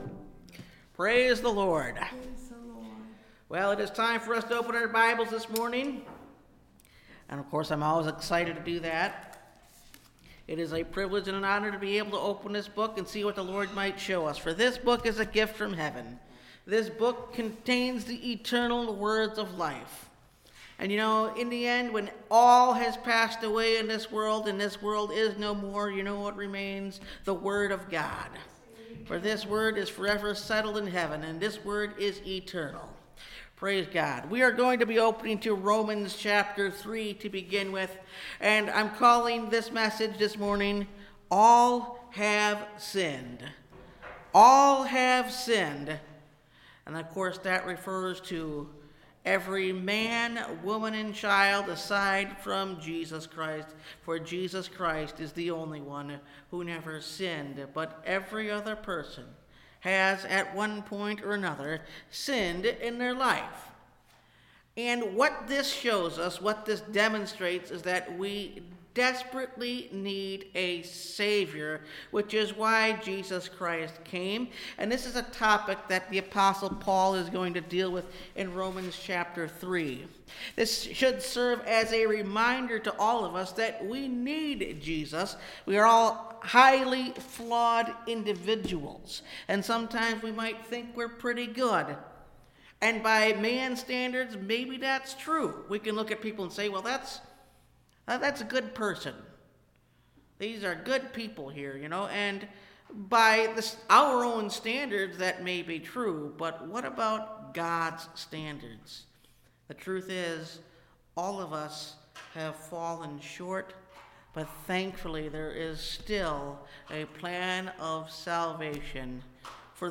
All Have Sinned (Message Audio) – Last Trumpet Ministries – Truth Tabernacle – Sermon Library